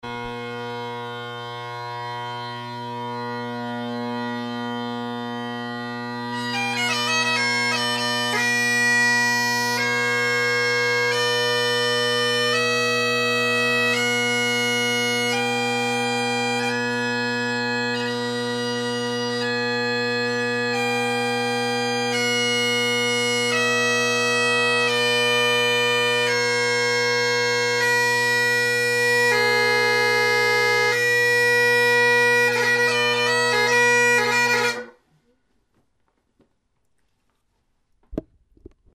Here is 2 above, canning tenors, selbie bass, same standing position as the above, so the chanter is pointing away from the microphone, drones toward the microphone (which is slightly lower than (my) head height). The scale is played up and then down.
A little tape on high G and F, low G is a little flat.
canningtenorsselbiebasschanter.mp3